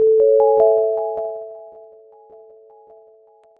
mail.wav